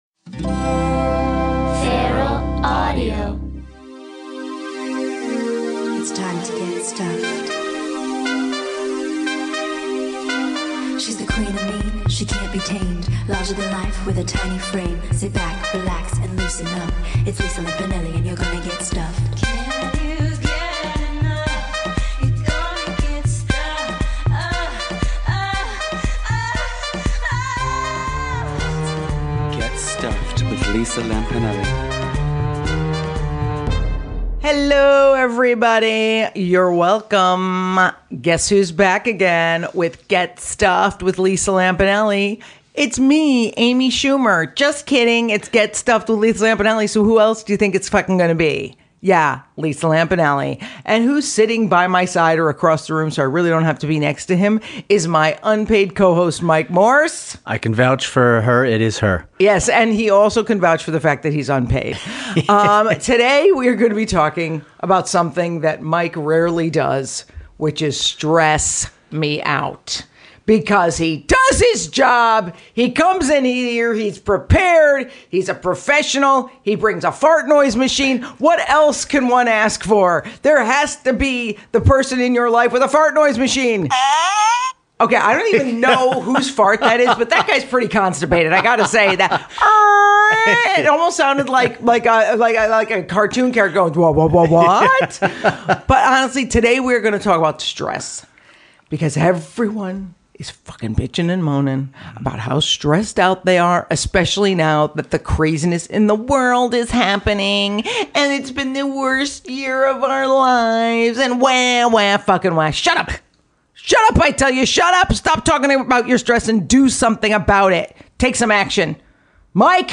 Plus, Lisa's pal from Celebrity Apprentice, Clay Aiken, joins the crew in the studio and, in a surprise twist, takes over the show!